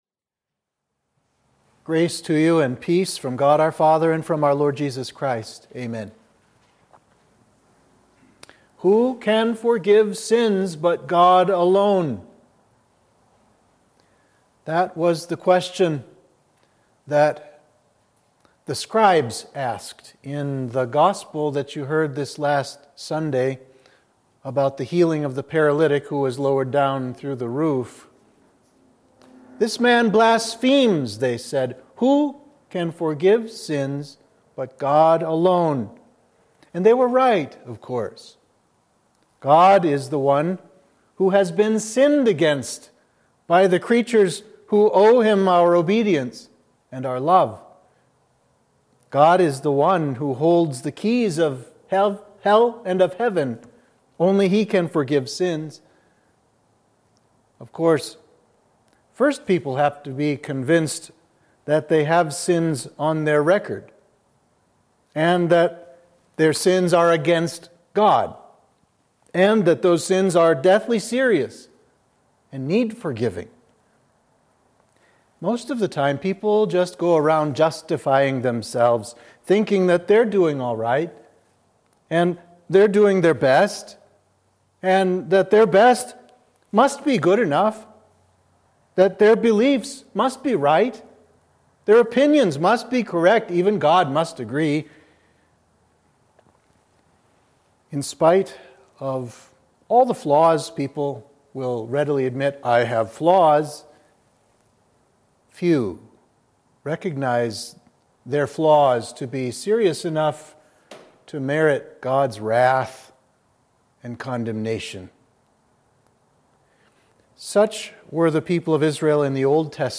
Sermon for Midweek of Trinity 19